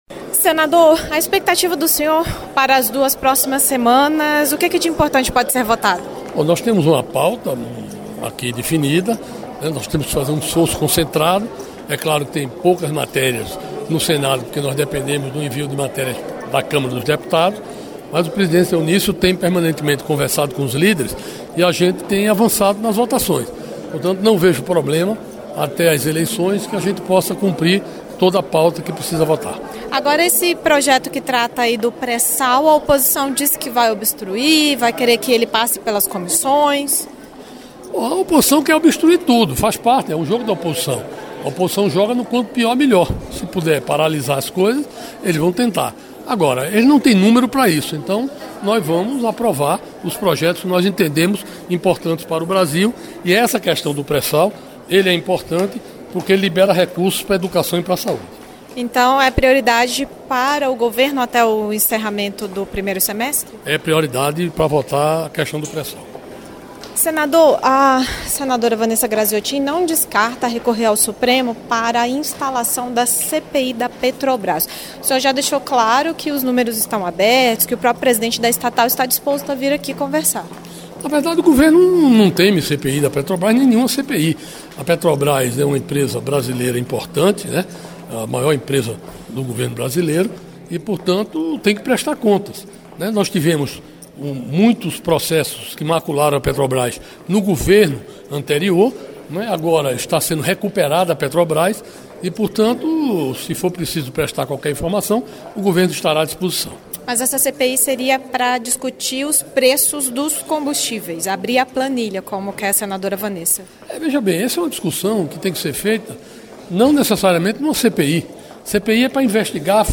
Jucá falou também sobre a proposta de criação da CPI da Petrobras. Ouça o áudio com a entrevista.